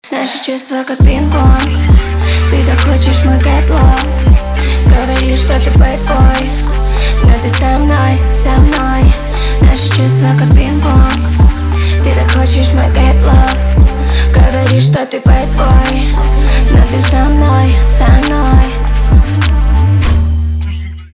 поп
битовые , басы